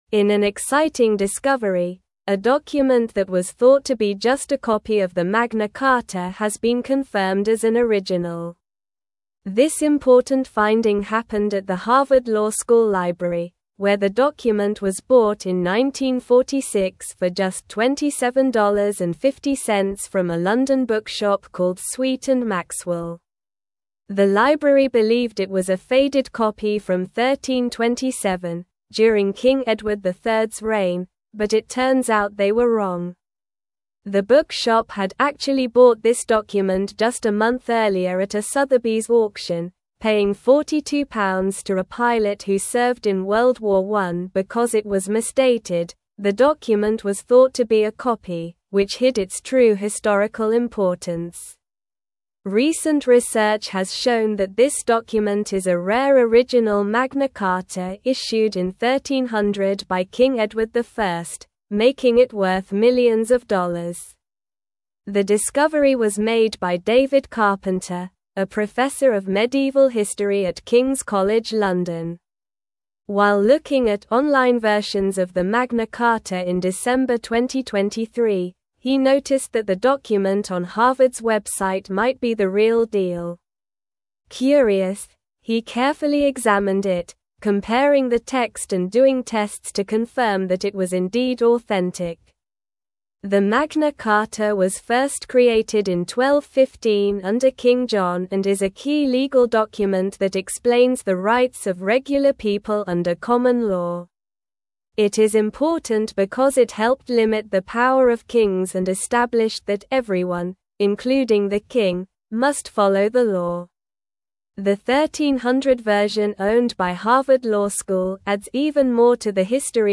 Slow
English-Newsroom-Upper-Intermediate-SLOW-Reading-Harvard-Library-Confirms-Original-Magna-Carta-Discovery.mp3